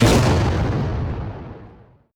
blast.wav